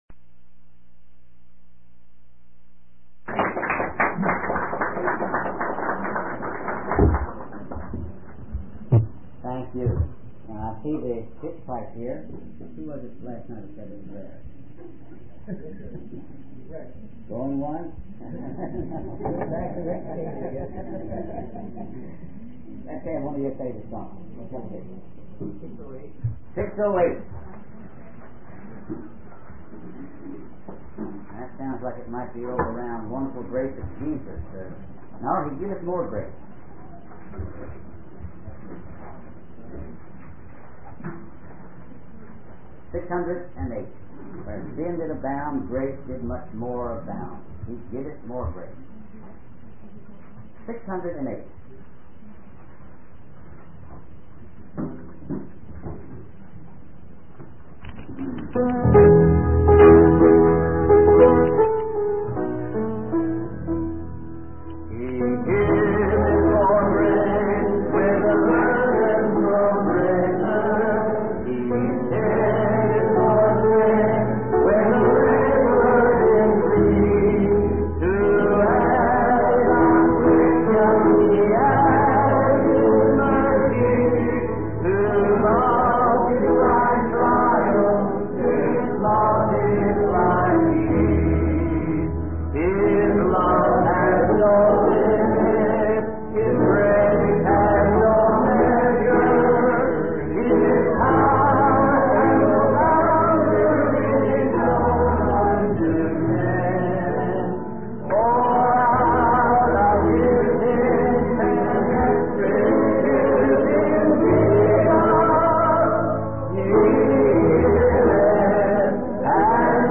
In this sermon, the preacher emphasizes the limitless love and boundless grace of God. He highlights that even when we reach the end of our own resources, God's grace is just beginning. The preacher references the Bible verse Romans 5:20, which states that where sin abounds, grace abounds even more.